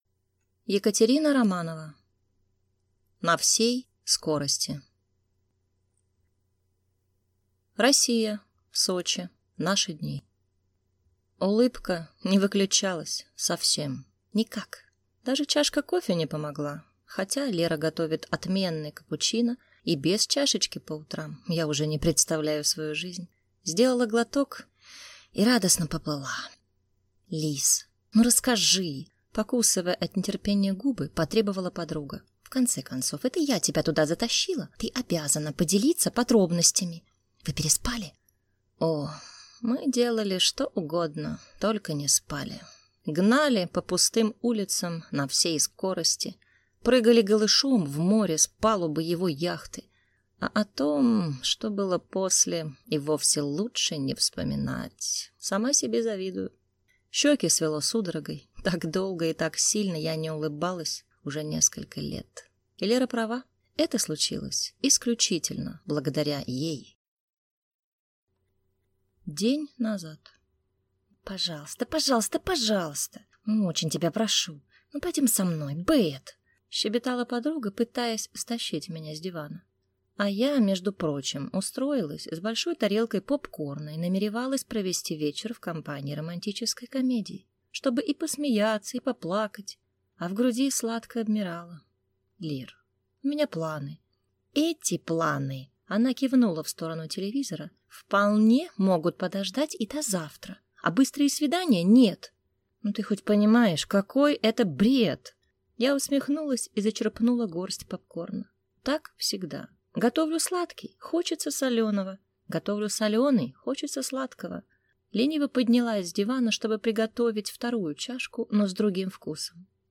Аудиокнига На всей скорости | Библиотека аудиокниг